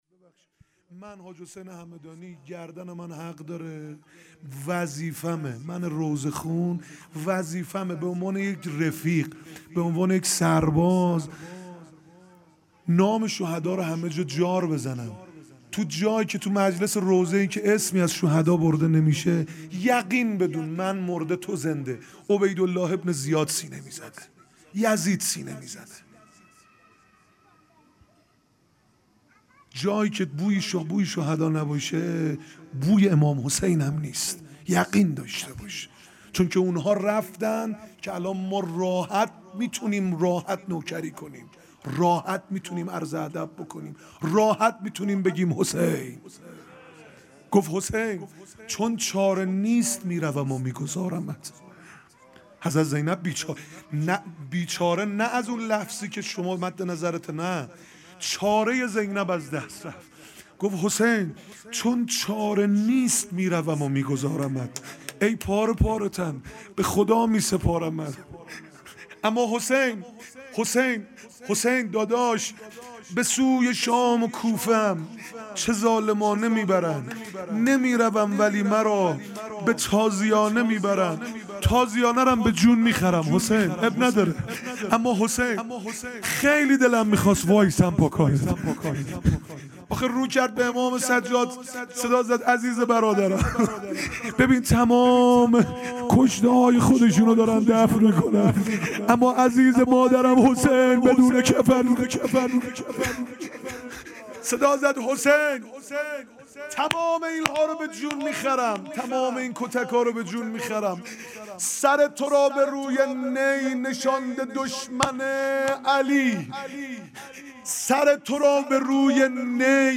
روضه پایانی شب سیزدهم محرم 1401
گلزار شهدای گمنام شهرک شهید محلاتی